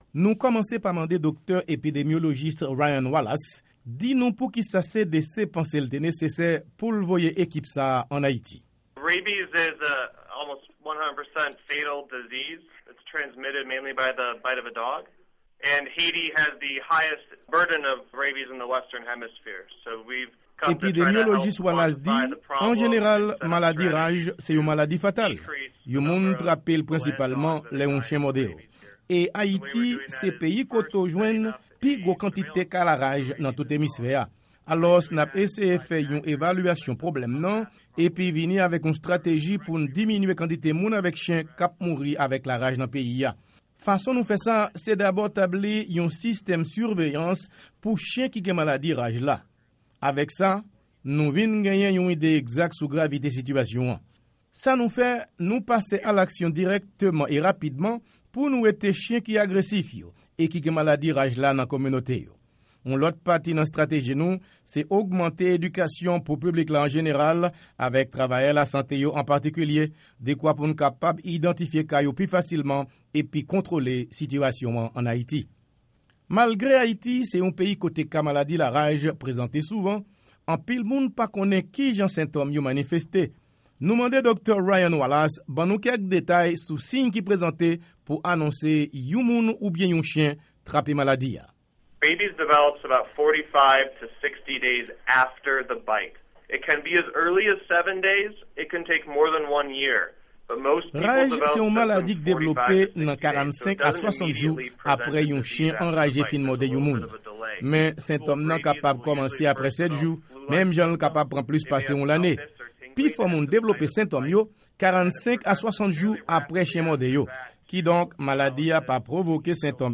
Repòtaj sou Asistans CDC nan Lit kont Maladi Laraj ann Ayiti - 11 avril 2014